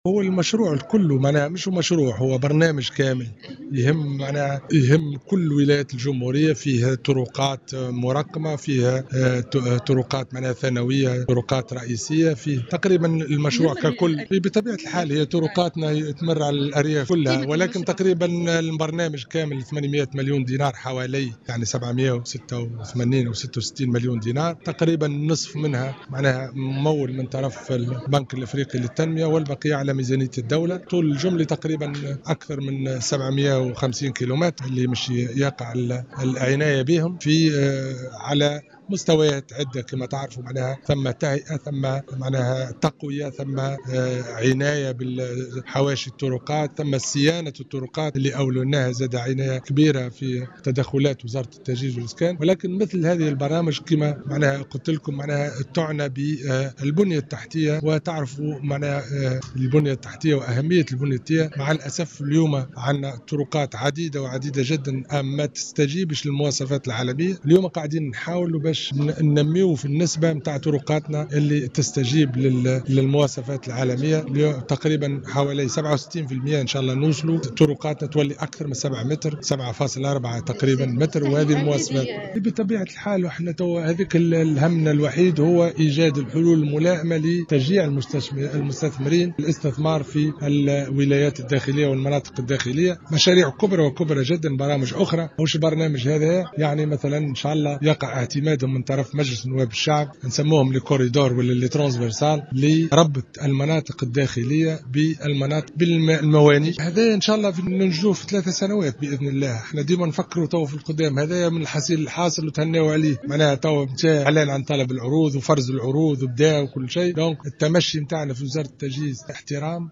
أعلن وزير التجهيز محمد صالح العرفاوي في تصريح للجوهرة أف أم اليوم الخميس 31 مارس 2016 أنه سيتم خلال بضع أسابيع الانطلاق في انجاز مشروع تعصير البنية التحتية للطرقات وخاصة الموجودة في المناطق الداخلية.